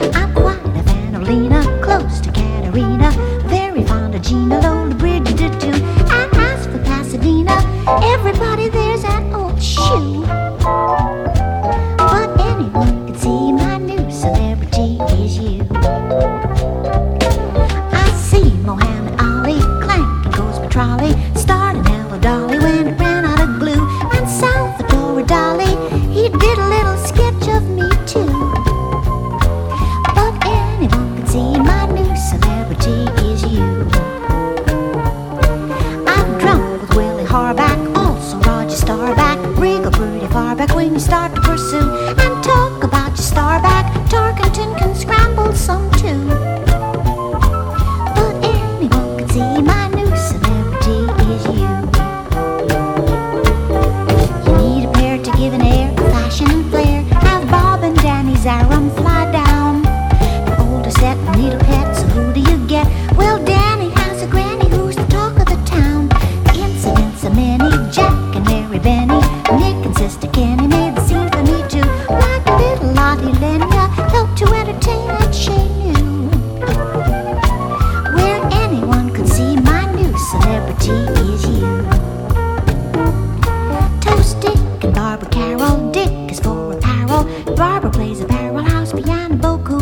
JAZZ / JAZZ VOCAL / EURO JAZZ / CONTEMPORARY JAZZ